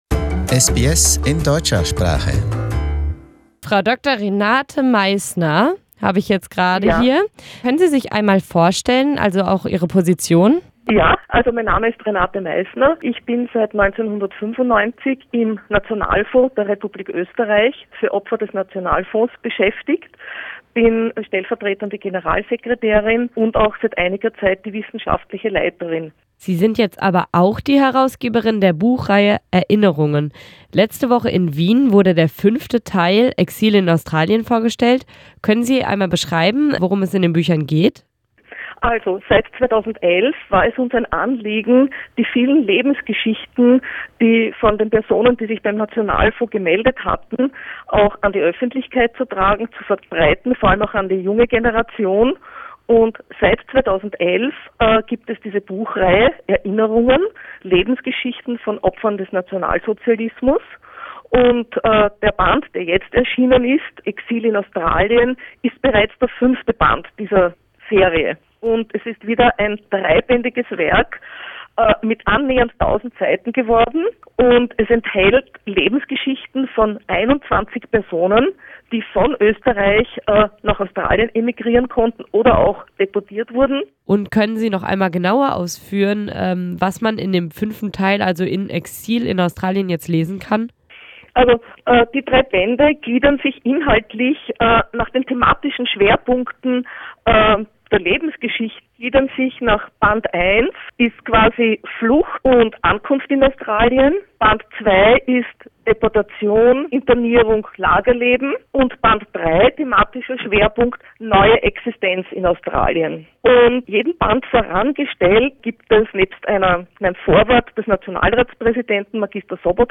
She explains in an interview what the books are about.